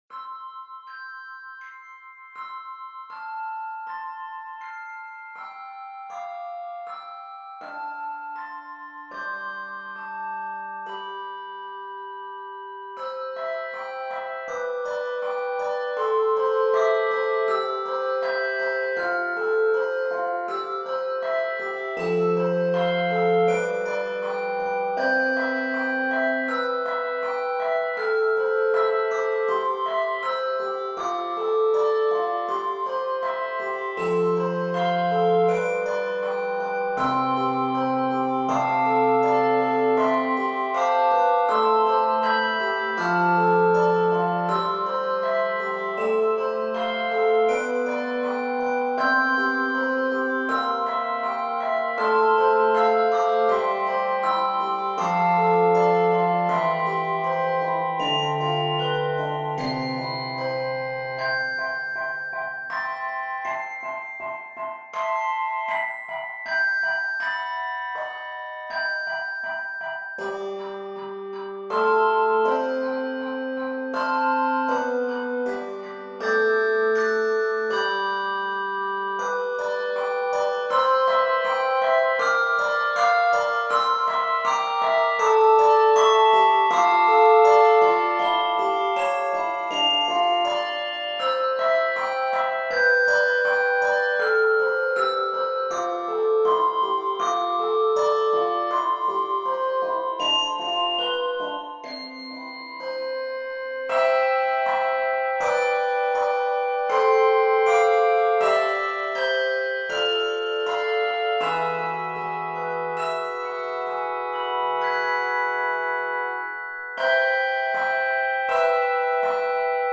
This lyrical and expressive arrangement
hymn tune
Key of C Major.